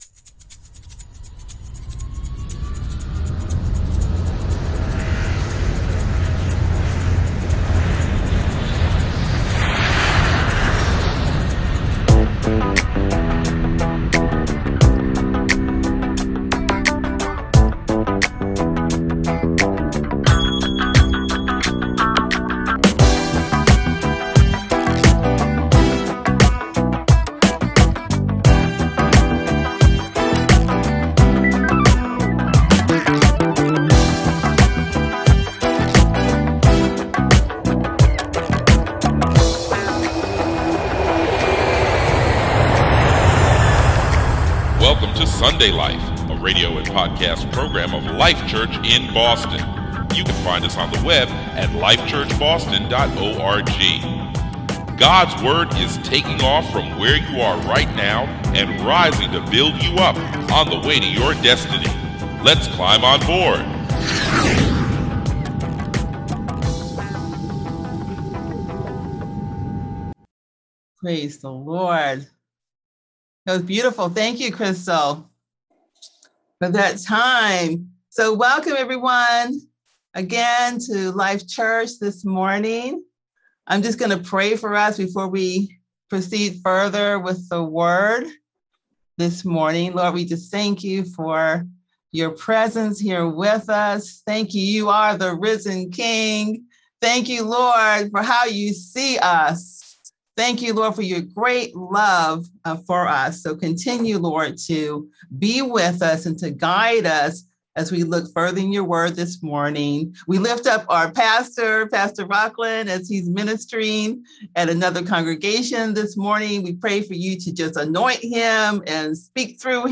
Sermon 2021-11-07
This is one of our Sunday sermons.
Sunday November 7, 2021 10:30 A.M. Message from Life Church Boston